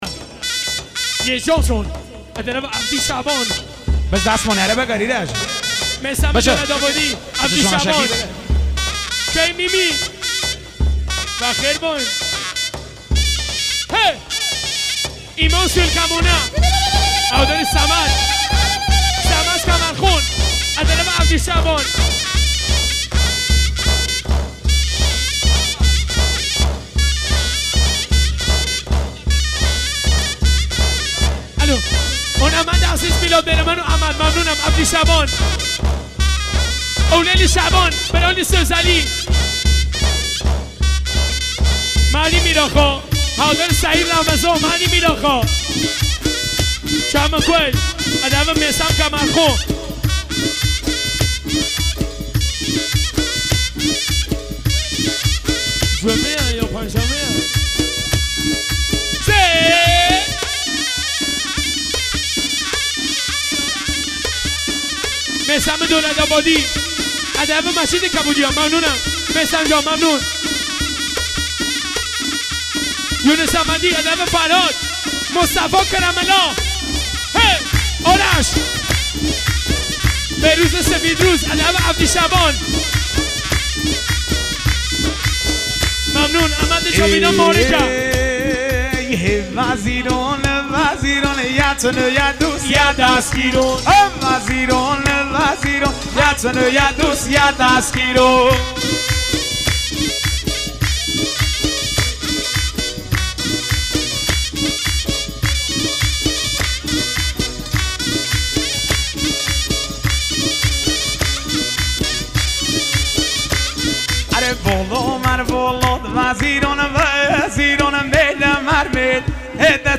اهنگ شاد لکی و لری با ارگ